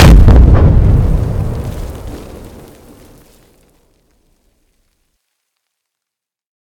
large-explosion-1.ogg